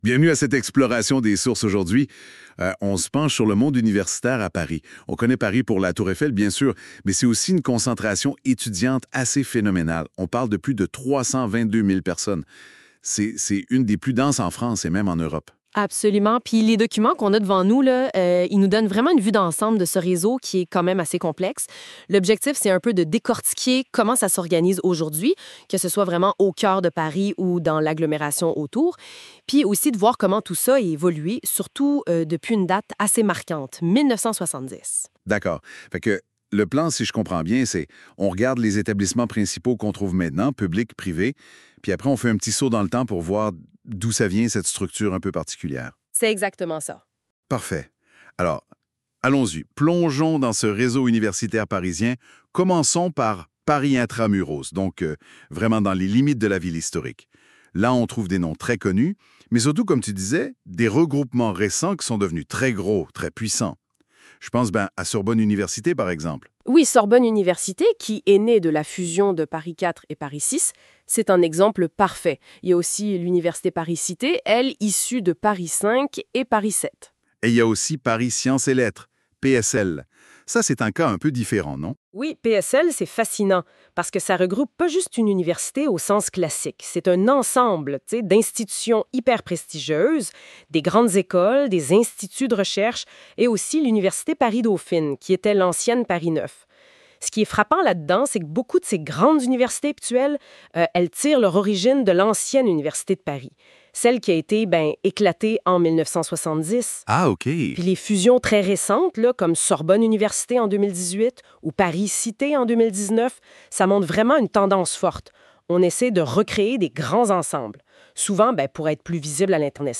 Québécois